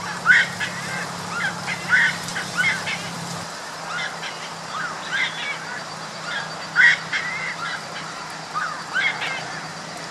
Saracuruçu (Aramides ypecaha)
Nome em Inglês: Giant Wood Rail
Fase da vida: Adulto
Detalhada localização: Camping Municipal Iberá
Condição: Selvagem
Certeza: Gravado Vocal